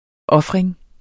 Udtale [ ˈʌfʁεŋ ]